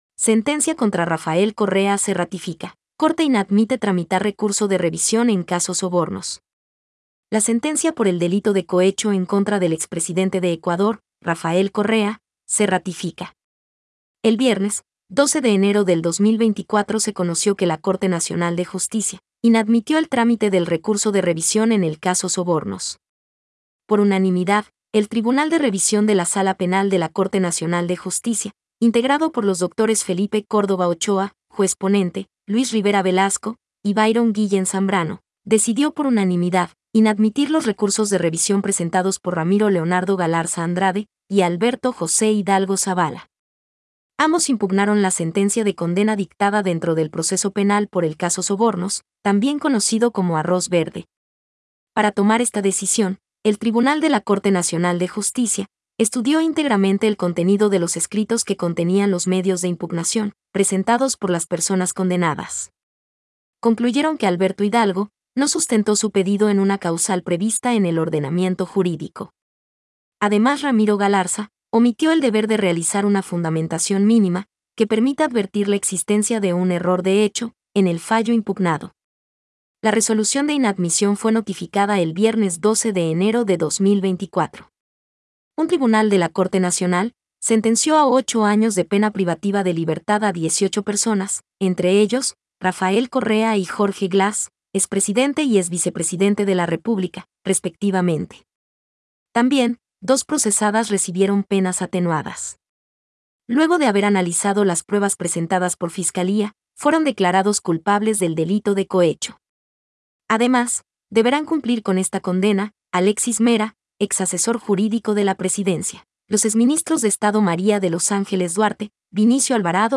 Noticia hablada